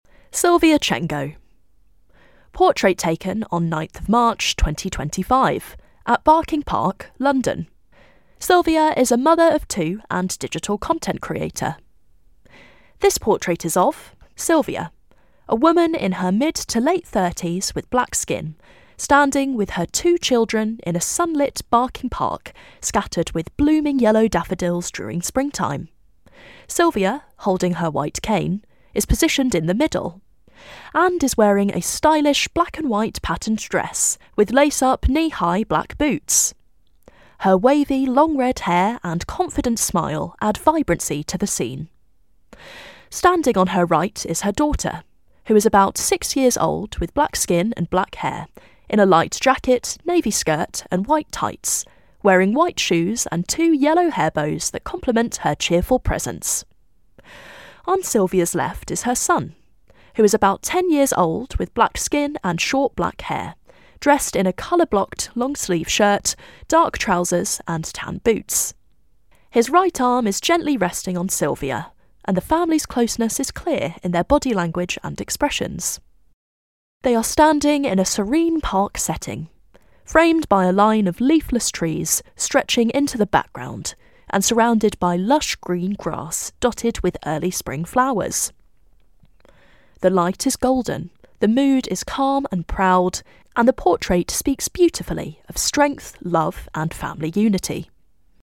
Seen Exhibition Audio Description